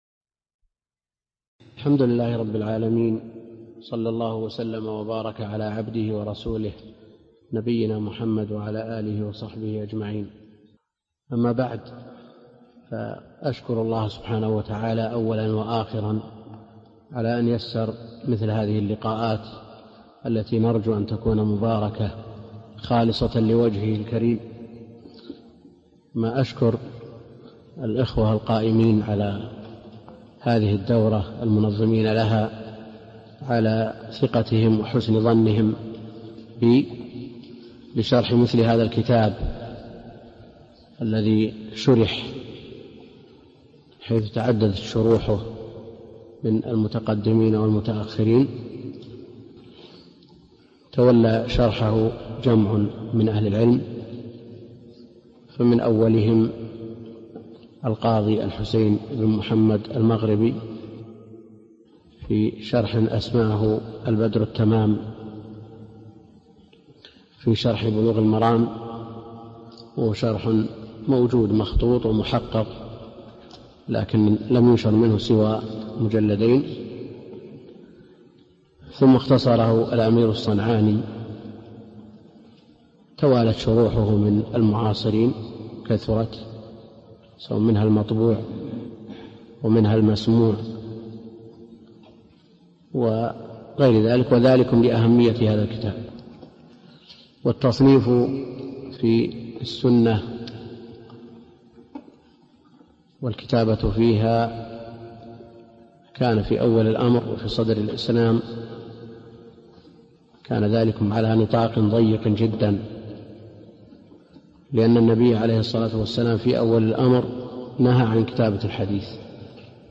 الدرس الأول من دروس شرح بلوغ المرام كتاب الطهارة للشيخ عبد الكريم الخضير